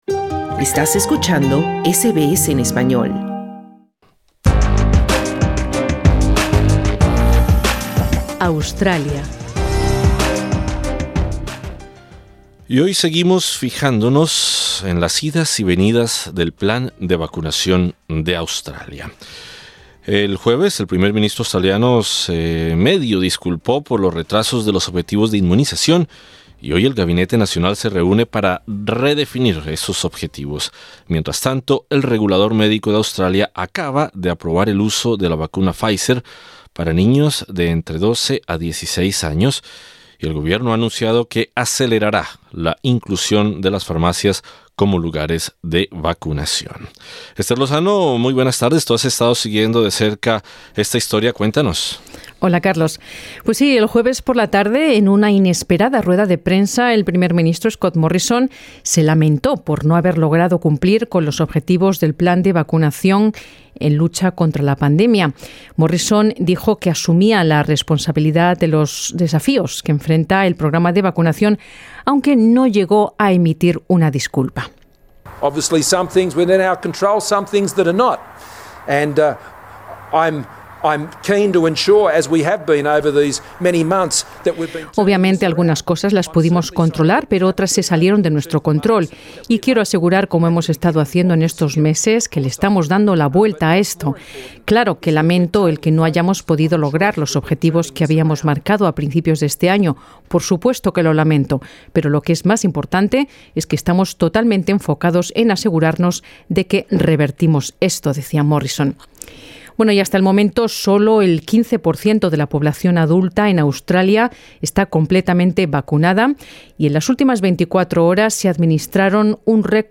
Pero ¿Cuán preparadas están las farmacias para unirse a este impulso del plan? En SBS Spanish entrevistamos